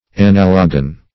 analogon - definition of analogon - synonyms, pronunciation, spelling from Free Dictionary Search Result for " analogon" : The Collaborative International Dictionary of English v.0.48: Analogon \A*nal"o*gon\, n. [Gr.
analogon.mp3